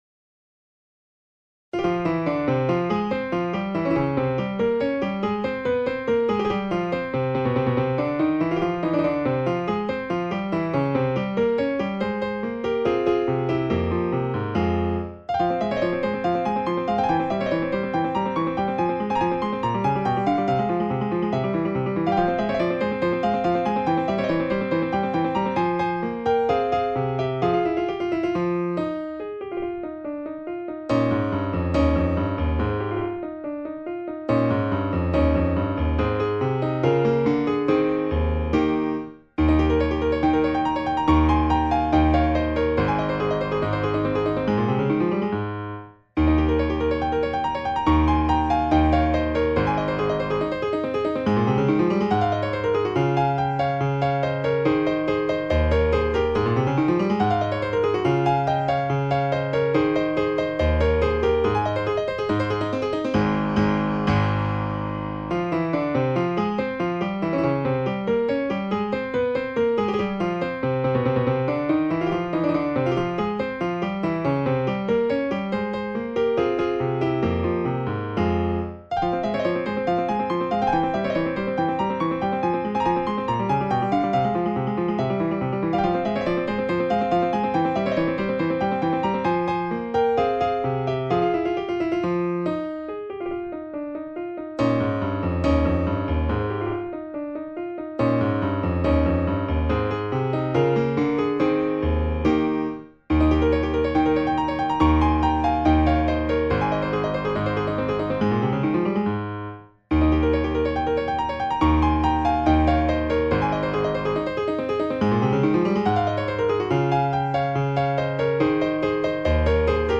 3 sonatas for piano